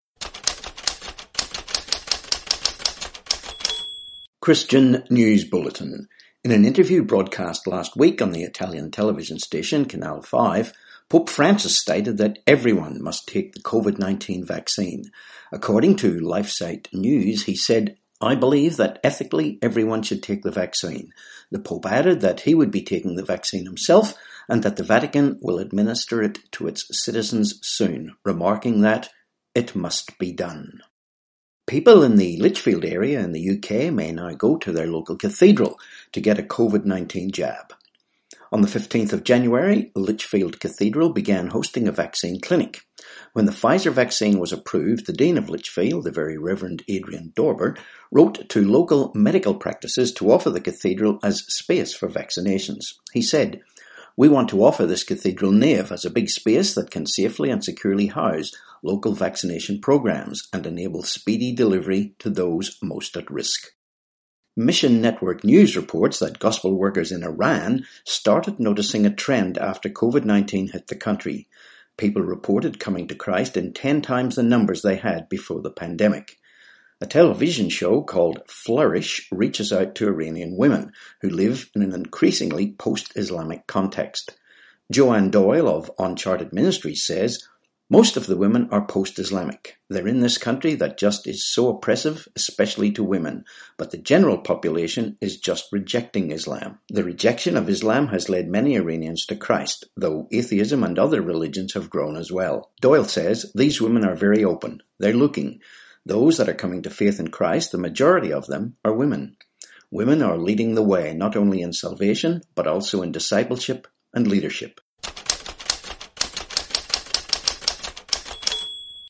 17Jan21 Christian News Bulletin